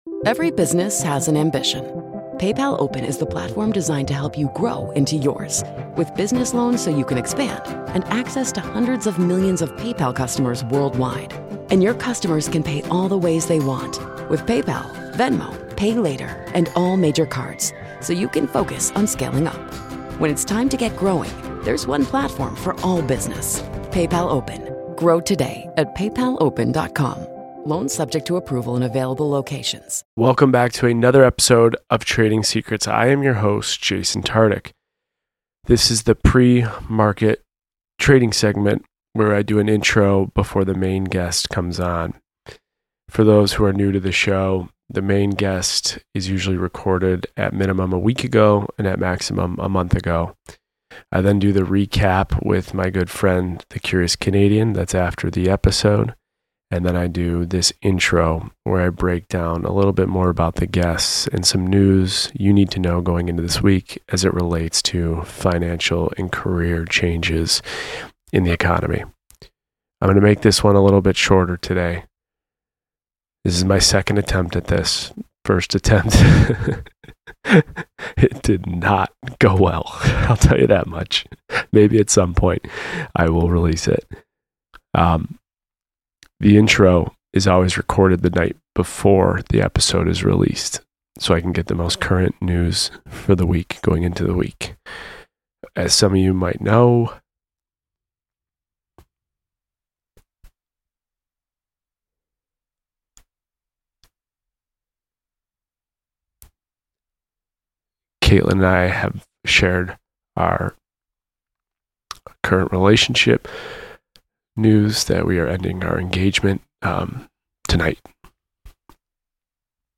Giannina and Blake give insight to how Blake has continued to make more every year as a DJ, how they balance their professional and personal lives with the industries they work in, how communication and trust are critical to the relationship, how they handle their relationship in the public eye and with public perception, and the ways they support each other’s careers. Plus, Jason talks money with Blake and Giannina with a series of 10 questions.